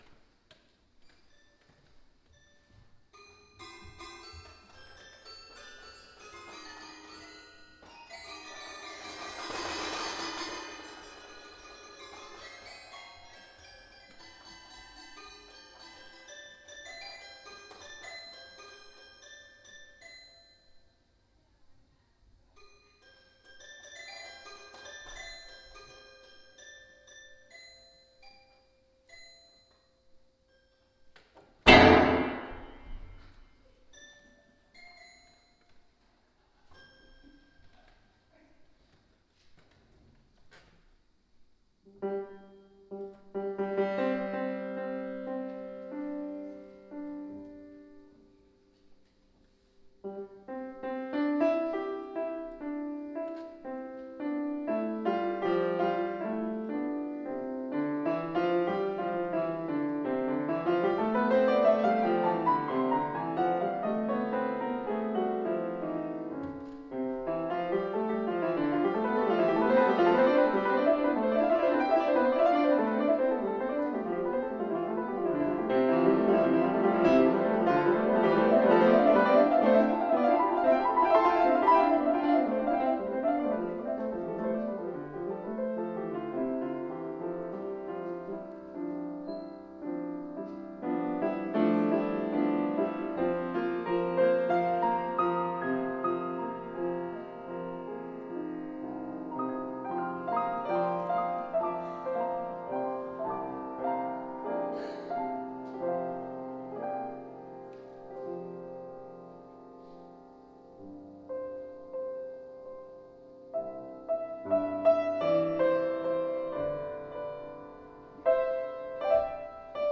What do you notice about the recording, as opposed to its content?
[Fuusm-l] OFFERTORY May 24, 2020